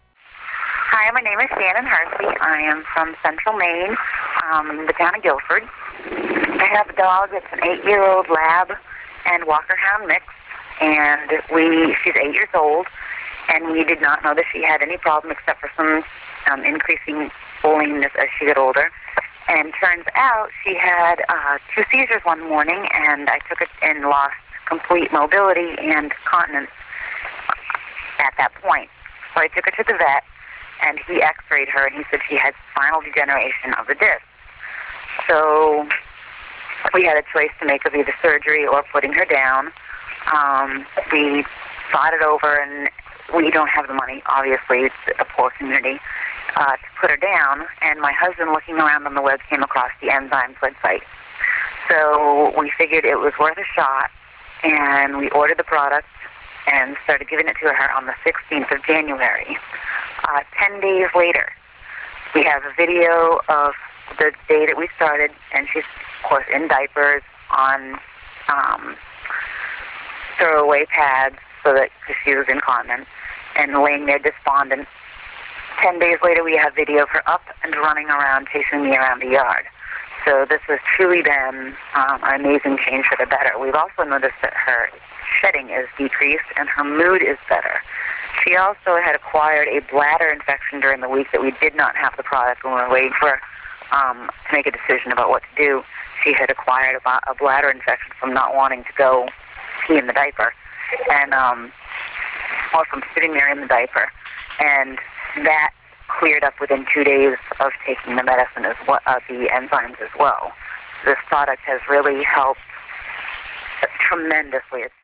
This page hosts a number of audio recordings of customer-provided verbal reviews on dog mobility troubles preventing their dogs from enjoying the life they deserve.